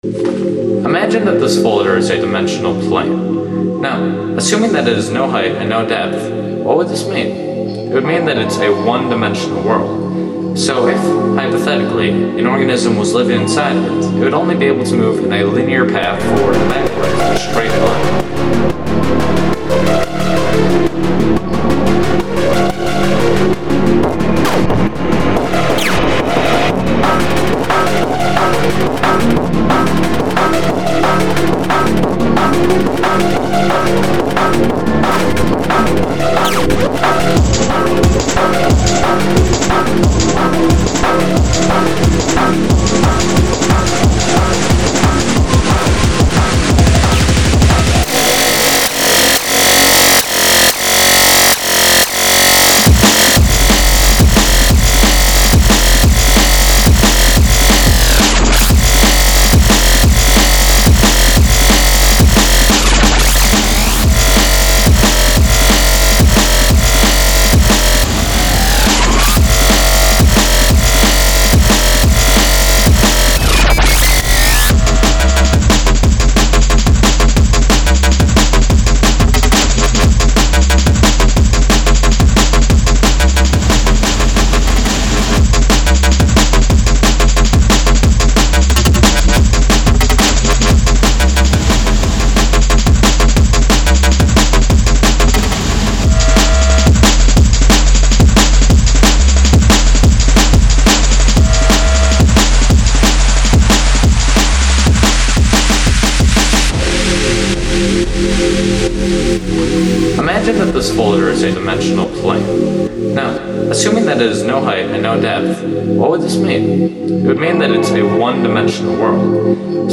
A Drum and Bass track, with a very futuristic buildup, and a batshit insane drop, that i tried to contain so it wouldn't straight up kill your ears lol. The drop itself is supposed to be raw sounding, and the buildup is supposed to be weird. The pads ended up being to my ears to atonal, so i copied the chords to an arp, so i could convery what melodies i was trying to say.